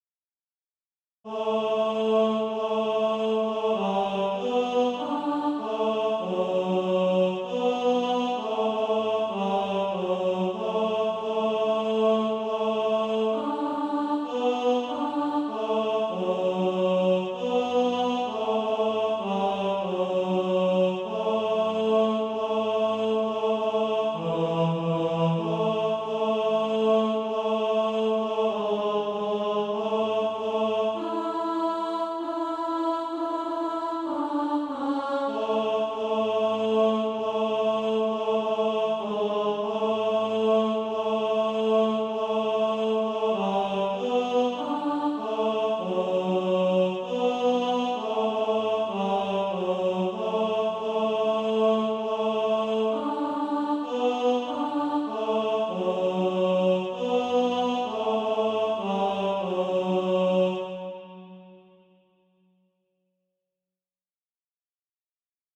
(SATB) Author
Tenor Track.
Practice then with the Chord quietly in the background.